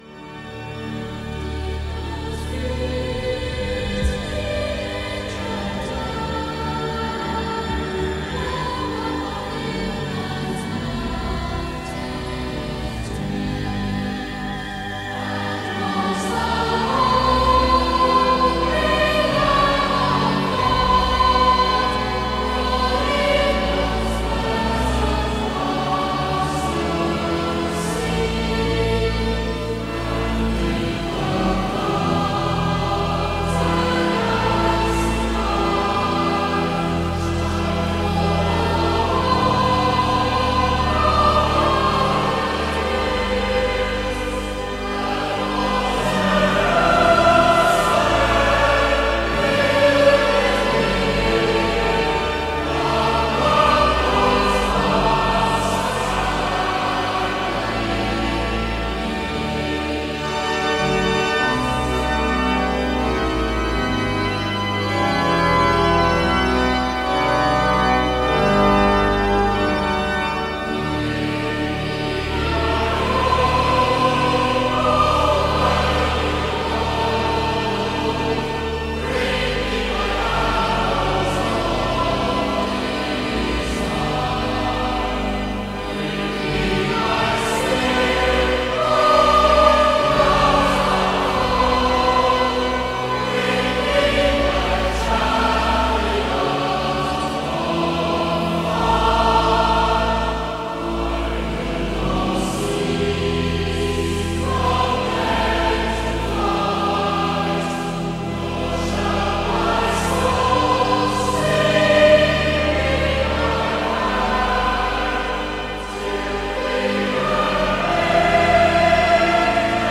PARRY (Hubert), Jerusalem - Westminster Abbey-.mp3